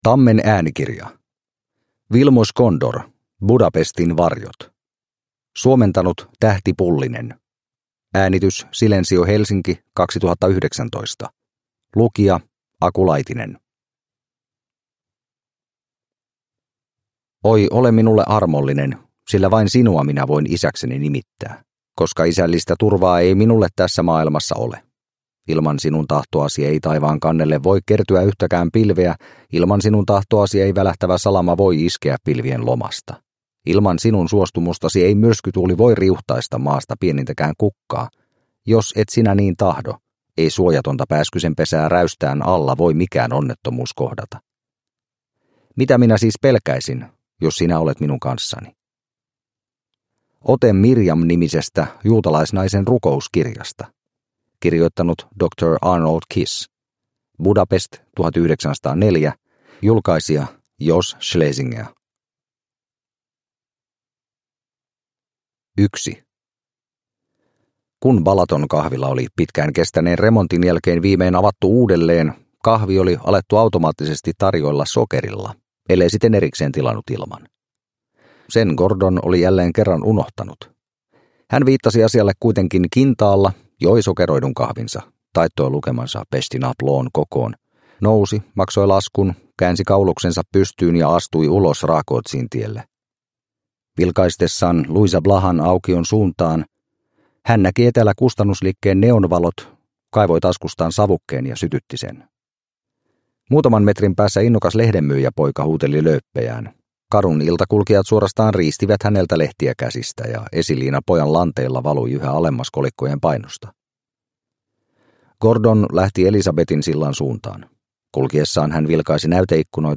Budapestin varjot – Ljudbok – Laddas ner